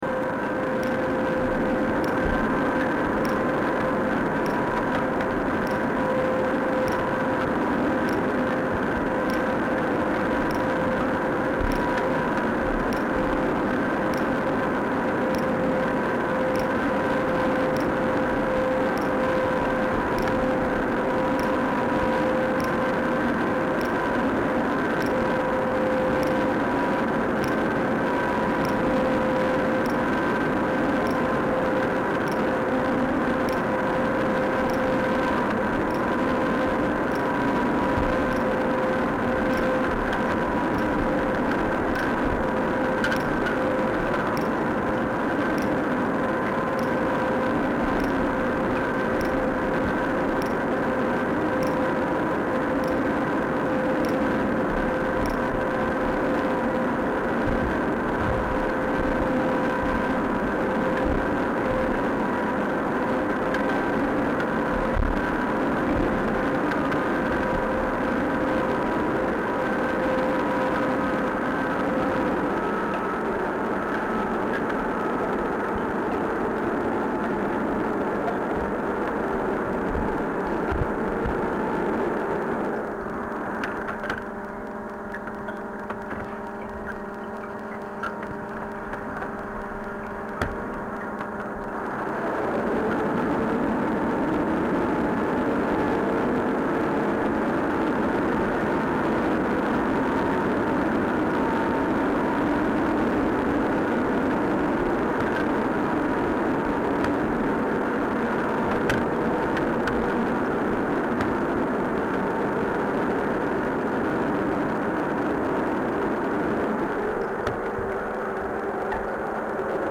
Underwater sound recording experiments November 4th /5th 2014
The two recordings below were made using my original DolphinEar hydrophone connected into the line input of an Olympus LS-11 recorder using the PCM 96 kHz 24-bit setting.
I took the boat to the area of the river mouth in Urquhart Bay where I hoped to let them sample the underwater soundscape.
The only other boat I had seen on Loch Ness all day was the Jacobite Warrior, and this was the sound of it approaching and manoeuvring into the Clansman Harbour over 5 km away, and not even in a direct line with us.